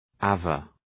Shkrimi fonetik {ə’ver}